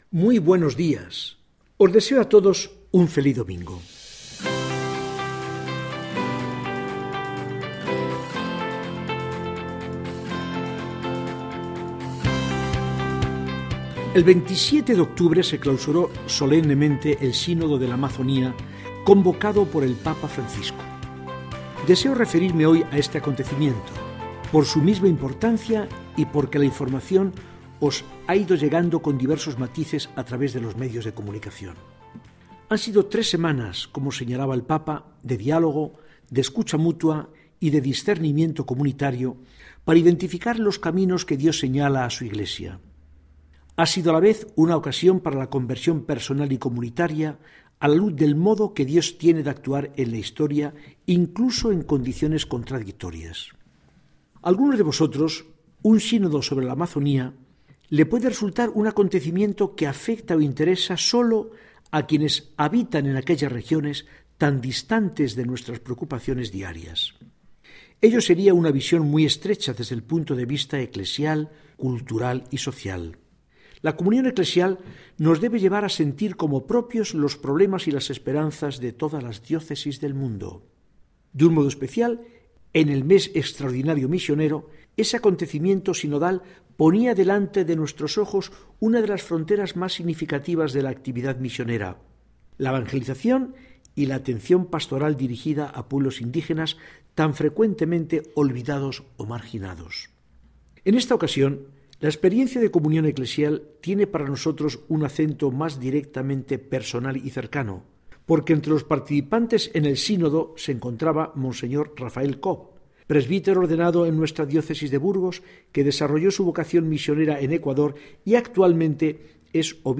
Mensaje del arzobispo de Burgos, don Fidel Herráez Vegas, para el domingo 10 de noviembre de 2019.